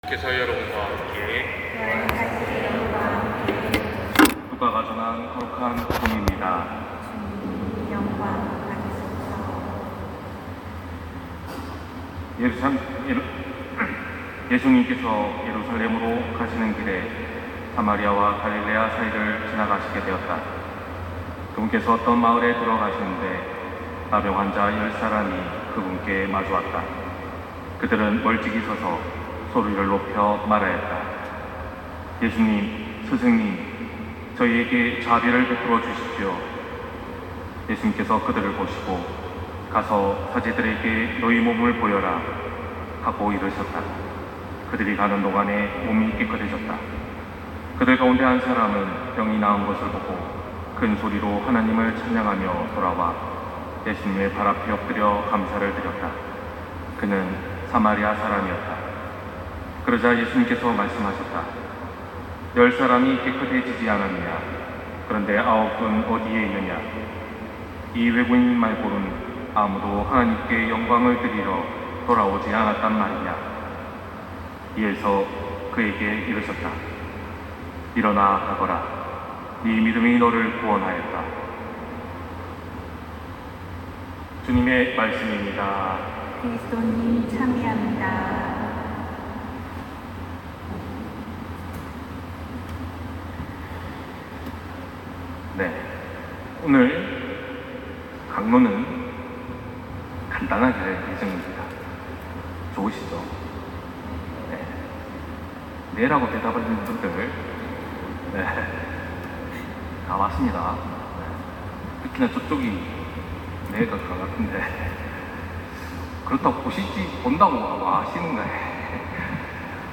251011 신부님 강론말씀